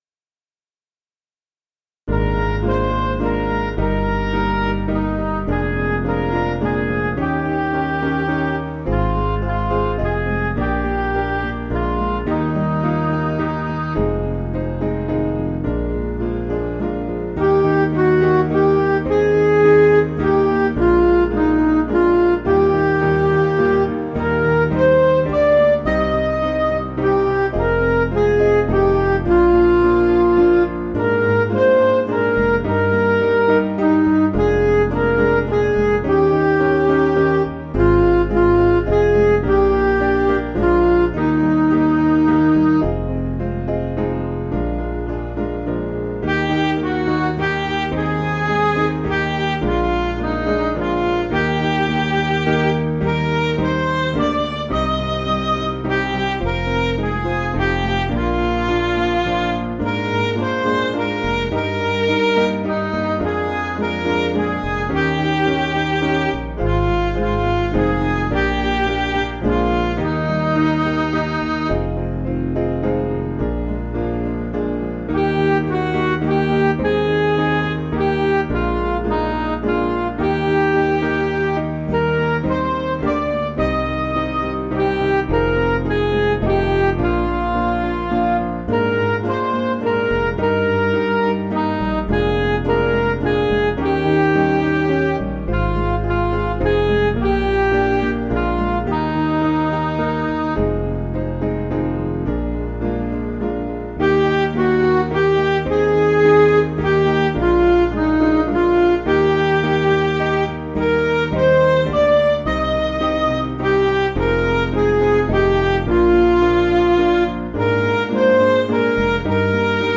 Piano & Instrumental
(CM)   4/Eb
Midi